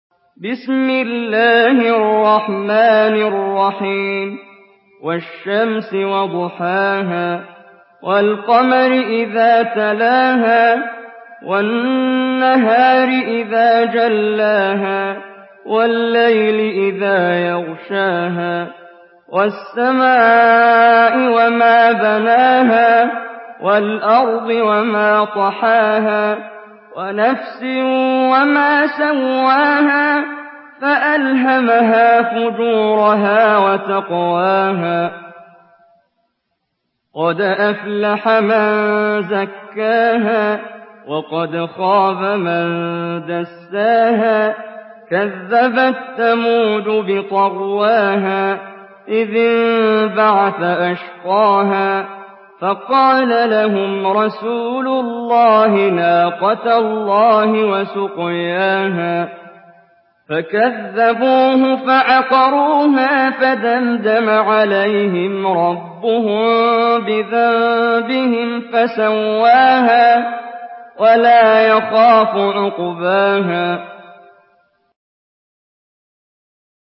Surah Ash-Shams MP3 by Muhammad Jibreel in Hafs An Asim narration.
Murattal Hafs An Asim